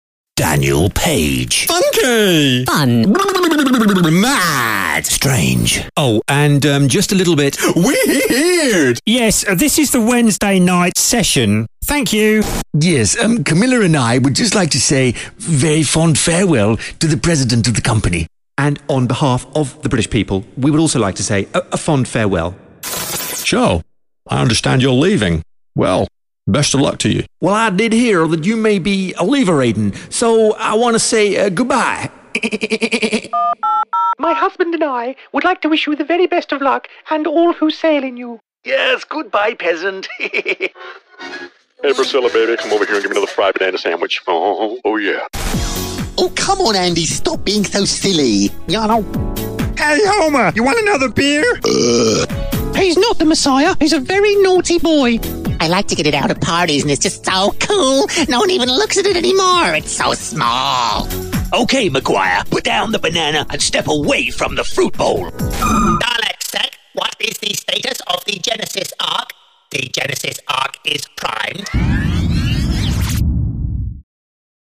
I specialise in cost effective, Received Pronunciation (accent-free), voice overs for film, television and radio.
britisch
Sprechprobe: Sonstiges (Muttersprache):